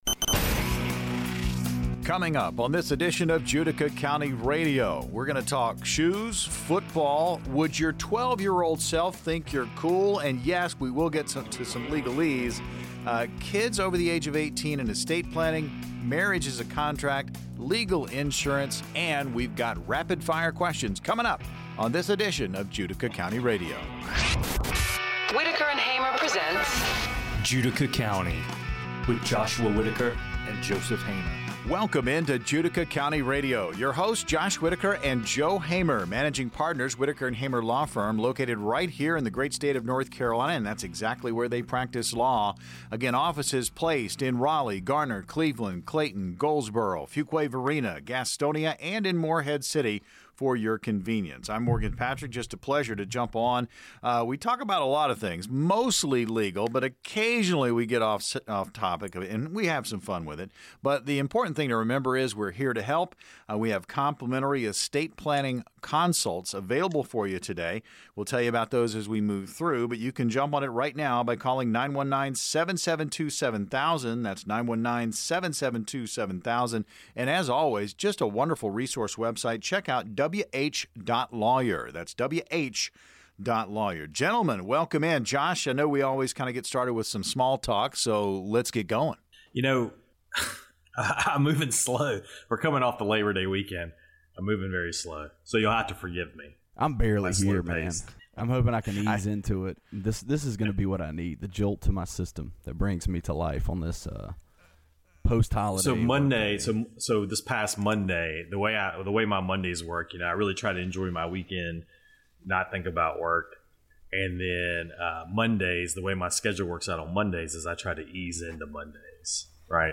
They delve into the legal aspects of marriage, emphasizing its nature as a contract, and explore the concept of legal insurance, highlighting its benefits. The episode also features rapid-fire questions that reveal personal insights and anecdotes from the hosts, making for an engaging and informative listen